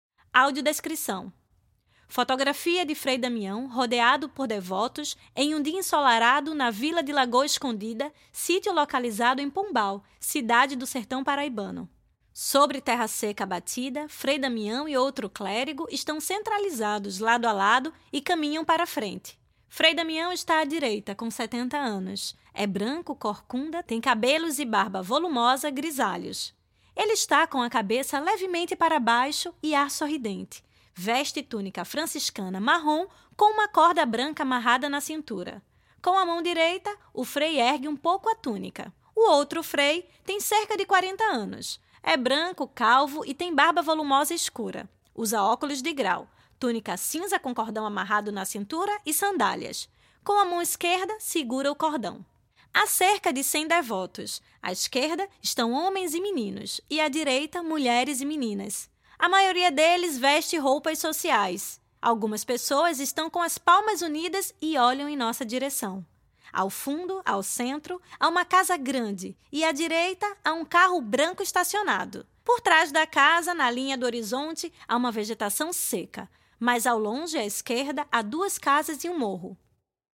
A câmera de Jorge Bodanzky durante a ditadura brasileira (1964-1985) - Audiodescrição | Estação 11 - Instituto Moreira Salles